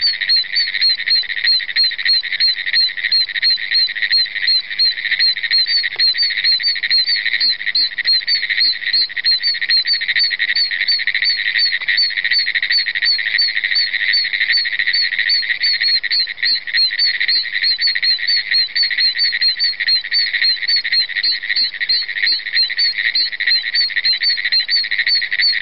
Two noisy little groups of croaksters are heard here! There's the Bufo Quercicus, and the Hyla femoralis.